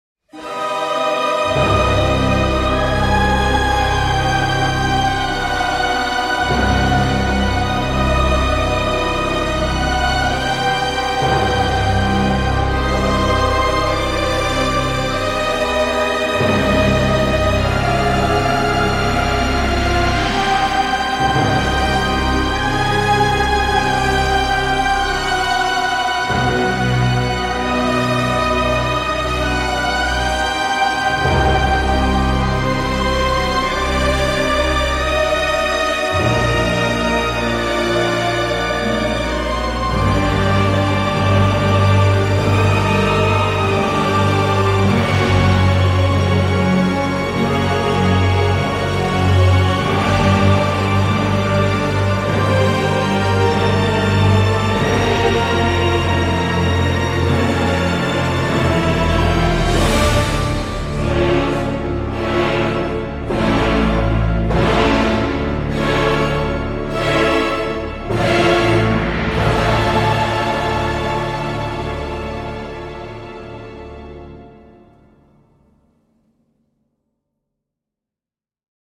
La noirceur de la musique s’intensifie, mais pas seulement.